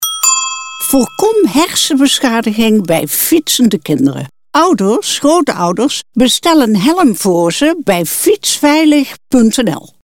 Stichting FietsVeiligNL zet door! - reclamespot